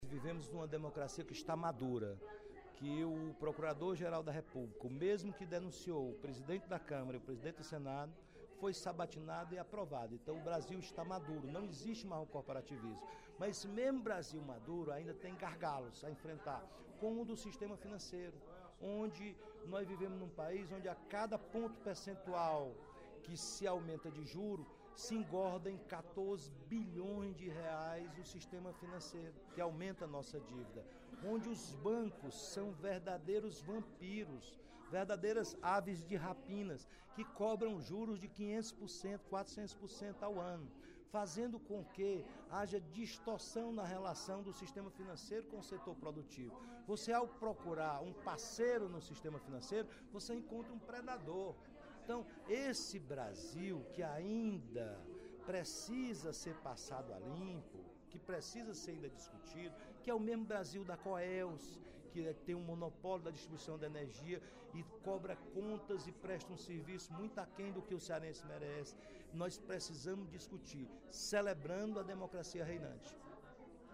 O deputado Roberto Mesquita (PV) reclamou, no primeiro expediente da sessão plenária desta quinta-feira (27/08), dos altos juros cobrados pelos bancos brasileiros.